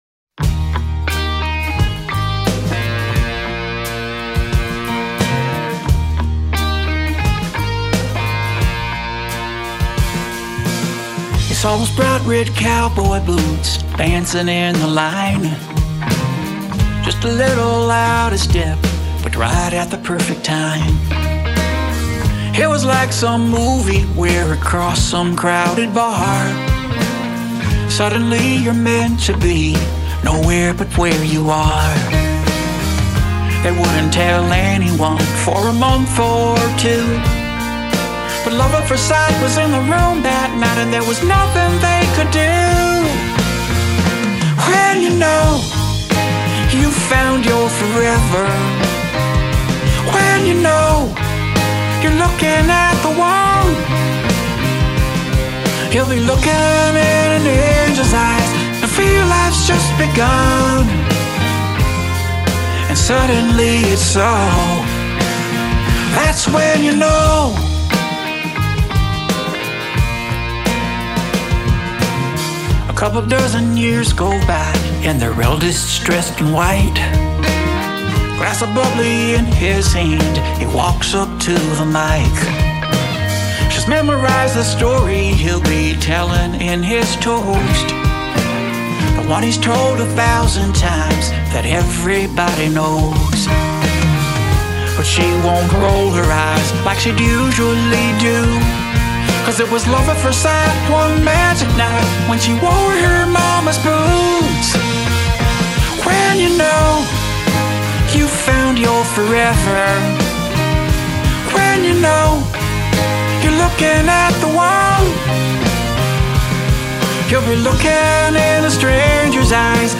Album version